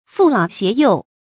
負老攜幼 注音： ㄈㄨˋ ㄌㄠˇ ㄒㄧㄝ ˊ ㄧㄡˋ 讀音讀法： 意思解釋： 背著老人，帶著孩子。